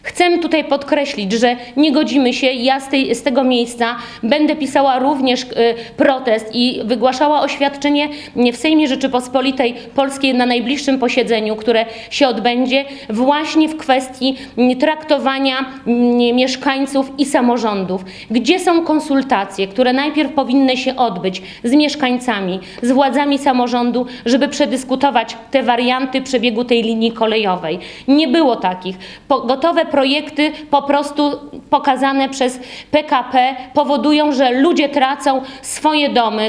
Zaniedbania w budowie dróg oraz planowana Rail Baltica były tematem konferencji prasowej, którą zorganizowała w piątek (11.10.19), w Suwałkach, Bożena Kamińska, posłanka Platformy Obywatelskiej.